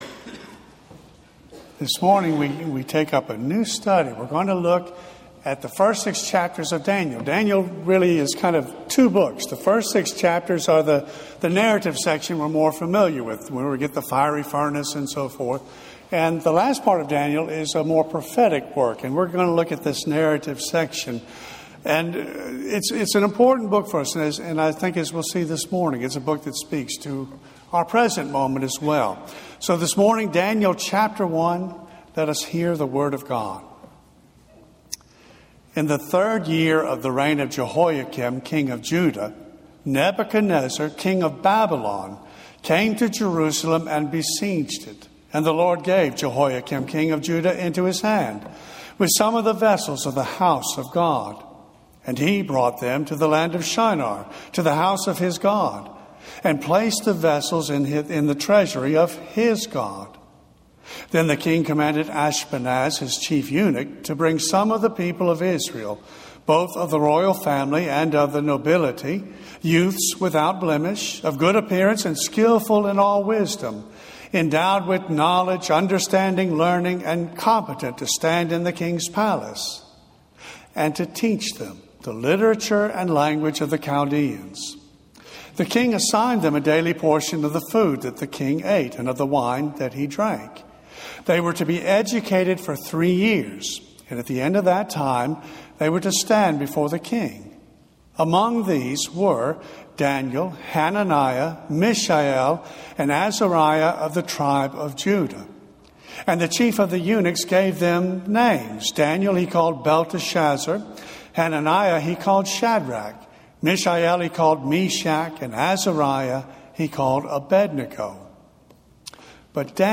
sermon-Aug-4-2024.mp3